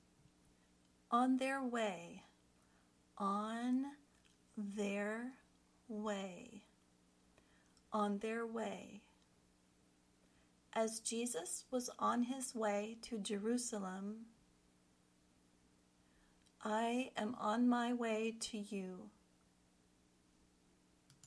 Then, listen to how it is used in the sample sentences.
ɑːn ðər ˈweɪ  (idiom)